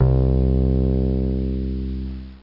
Bass Lo Sound Effect
Download a high-quality bass lo sound effect.
bass-lo.mp3